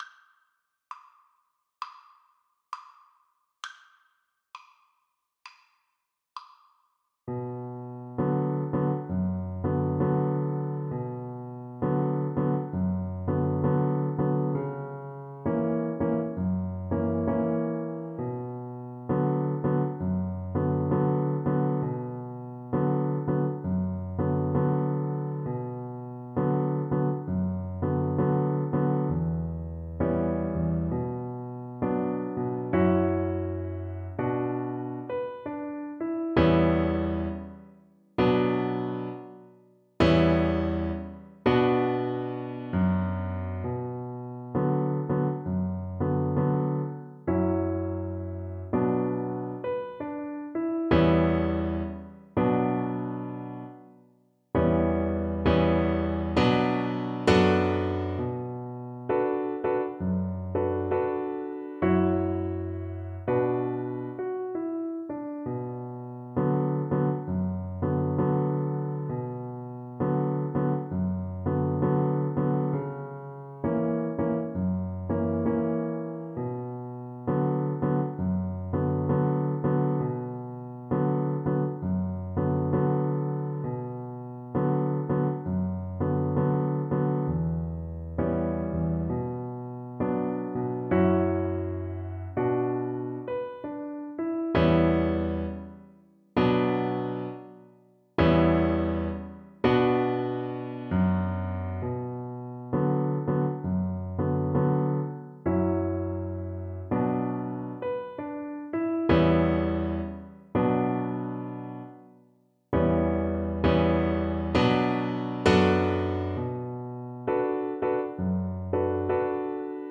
4/4 (View more 4/4 Music)
Moderate Gospel , Swung = c.110